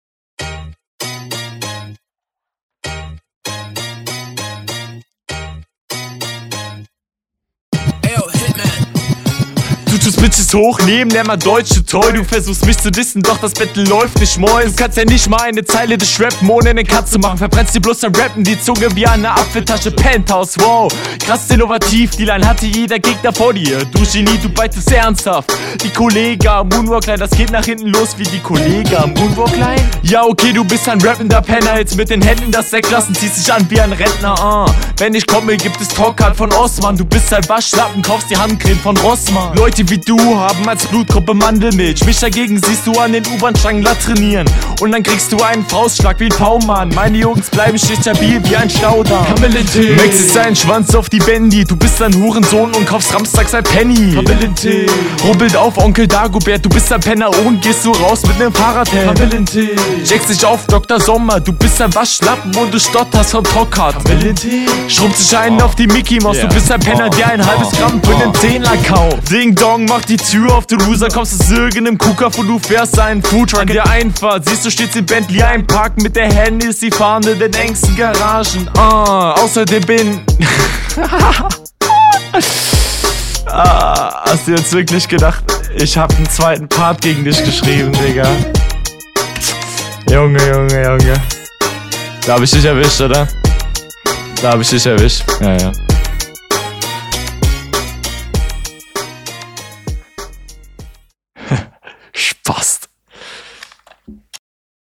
Die Soundquali ist leider ne Katastrophe.